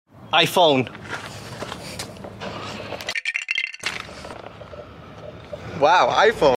iPhone Mp3 Sound Effect